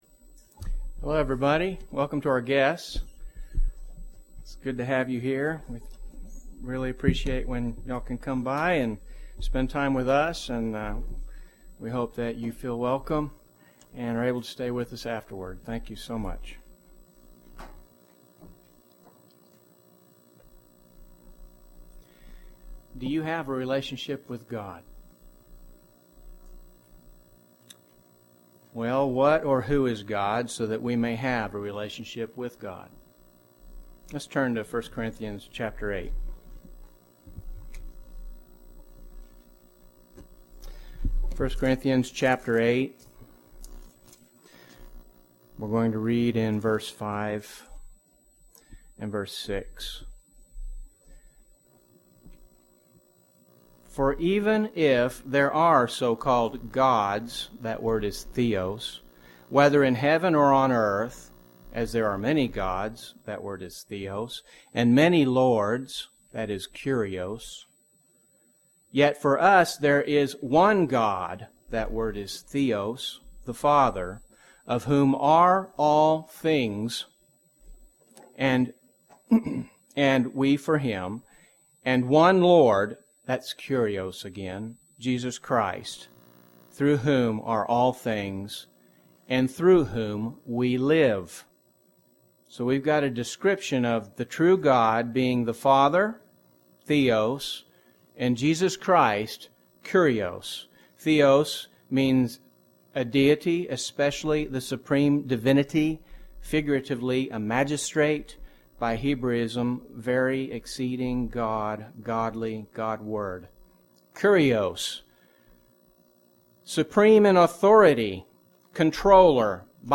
UCG Sermon God dwelling in you Notes PRESENTER'S NOTES Do you have a relationship with God?